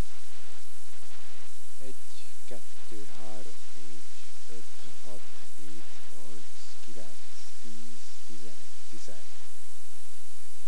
The voice is almost inaudible, and there is that constant hiss.
If you apply the “Normalization” effect to the sound, with both of the tick boxes ticked, then the DC offset can be corrected and the recording amplified to a higher level, but there is still a massive amount of hiss (the first couple of seconds are supposed to be silence).